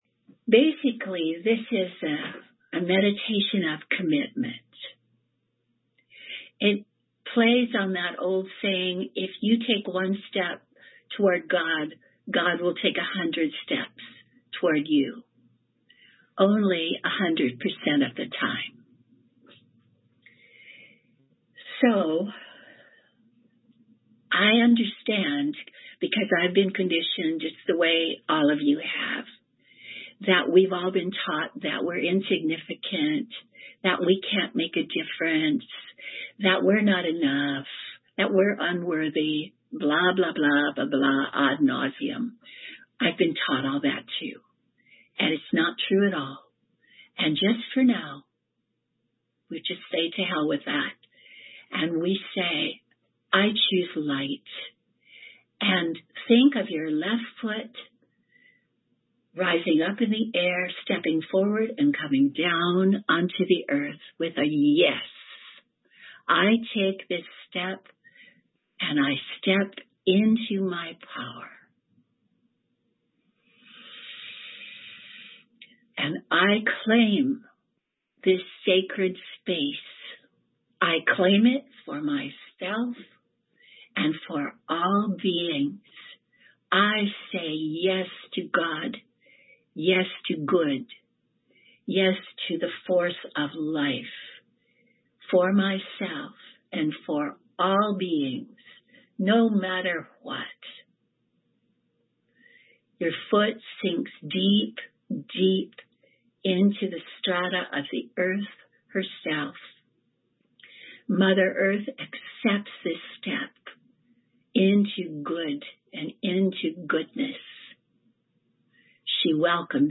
This short but powerful meditation on ‘claiming and holding sacred space,’ is newly excerpted from the Online Teachings. It is only about 5 minutes long, but helps us all anchor and to be able to hold steady in this work. This is a downloadable audio file, which we hope you will find useful: Claiming and Holding Sacred Space meditation